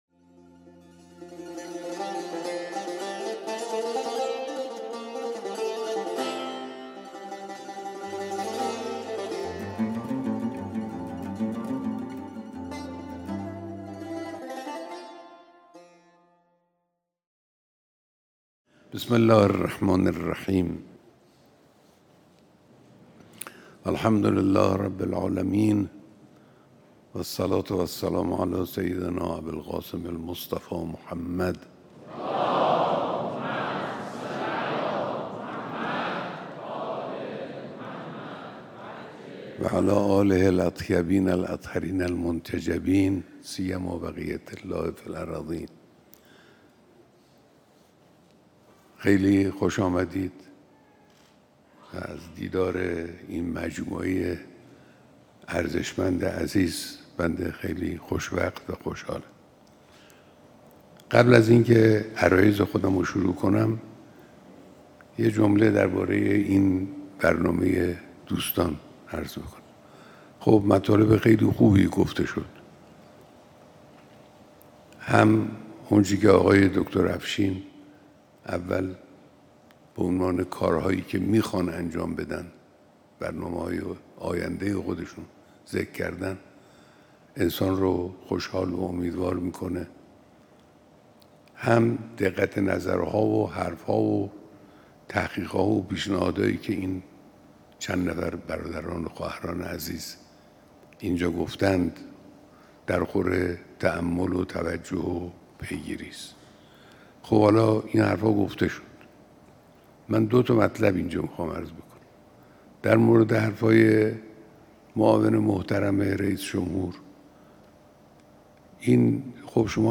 بیانات در دیدار نخبگان علمی، نفرات برتر المپیادها و آزمون‌ ورودی دانشگاه